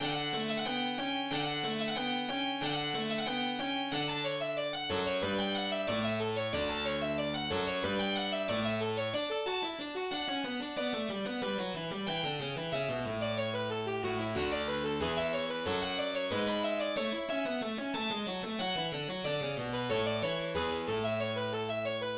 majeur2/2, Allegro, 81 mes.
La sonate K. 511 (F.455/L.314) en majeur est une œuvre pour clavier du compositeur italien Domenico Scarlatti.
La sonate K. 511, en majeur, notée Allegro, forme une paire avec la sonate suivante.
La seconde partie comprend de nombreuses modulations[1].
Premières mesures de la sonate en majeur K. 511, de Domenico Scarlatti.